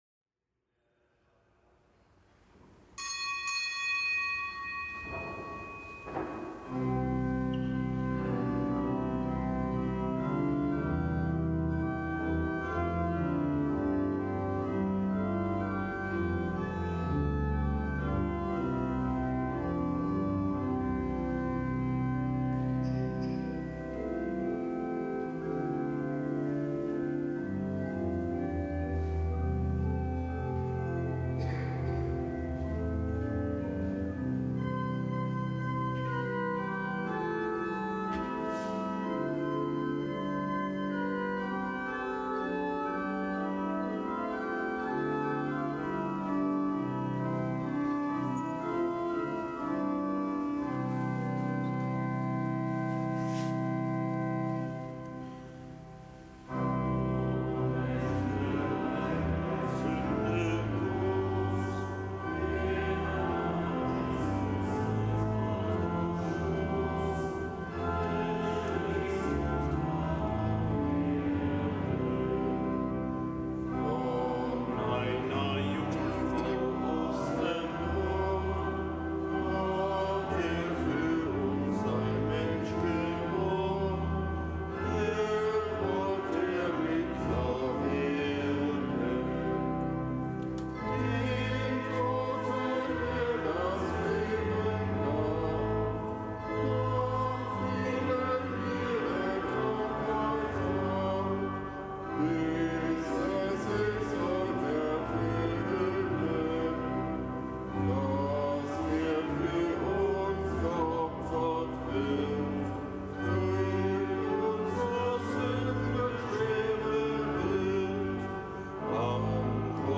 Kapitelsmesse aus dem Kölner Dom am Montag der dritten Fastenwoche.